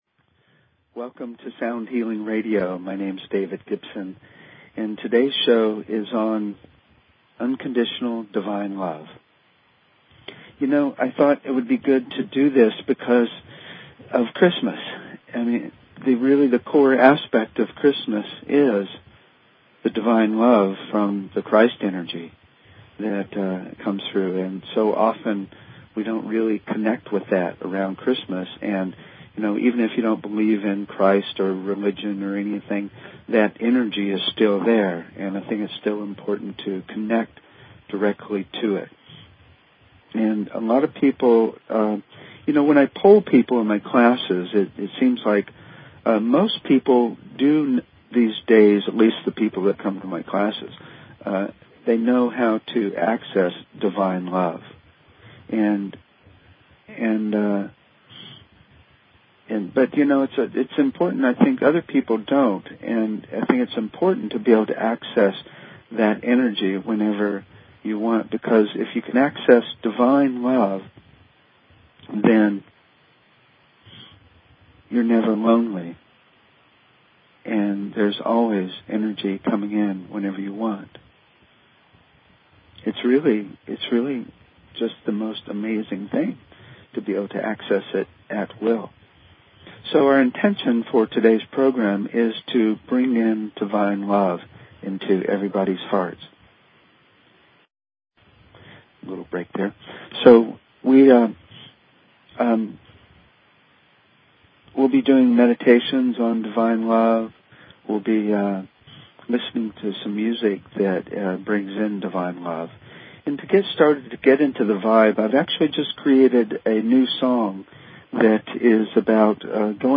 Talk Show Episode, Audio Podcast, Sound_Healing and Courtesy of BBS Radio on , show guests , about , categorized as
We will talk about, do meditations and listen to music that connect us to divine love.